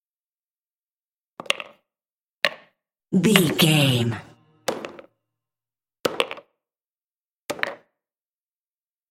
Billards ball in score
Sound Effects